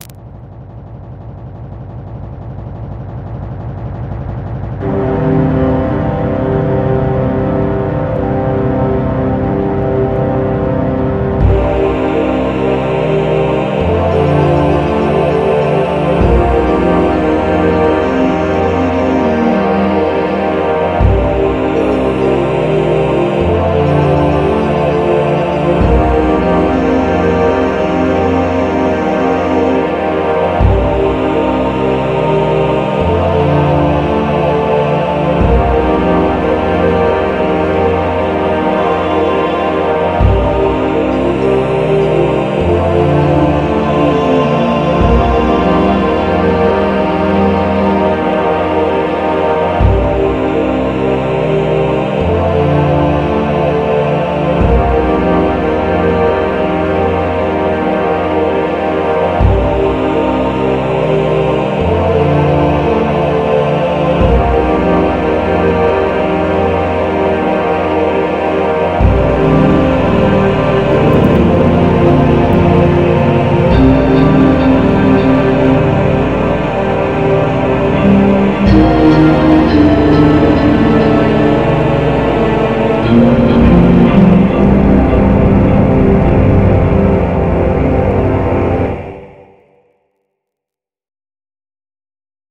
MIDI-Combo Cover